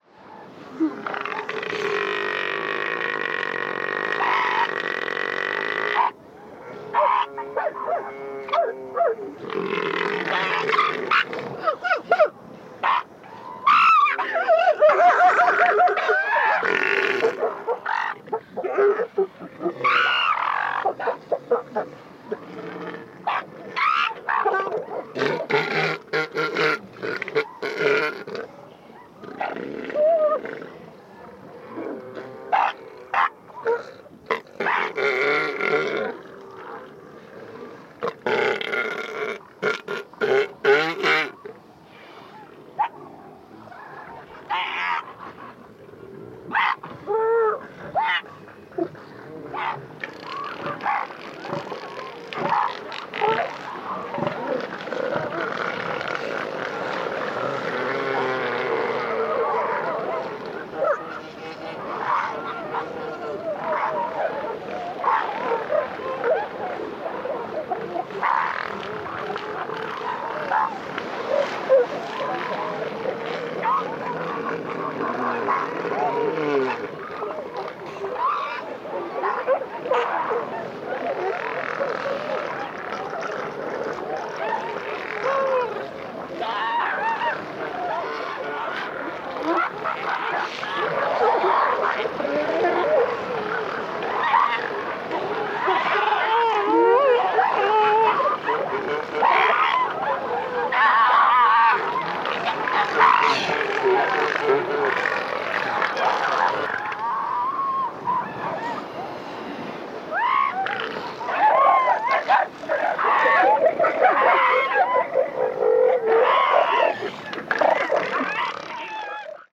На этой странице собраны разнообразные звуки морского слона – от мощного рева самцов до нежных голосов детенышей.
Голоса стаи морских слонов в дикой природе